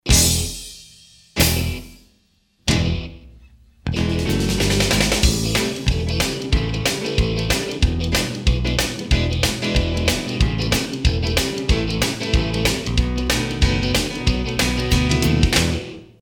Country Music Samples
Country 91a